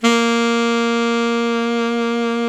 SAX ALTOMP05.wav